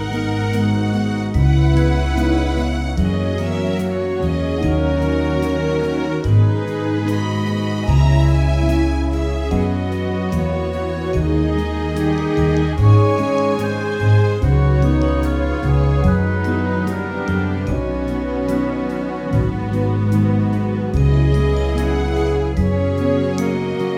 no Backing Vocals Christmas 3:27 Buy £1.50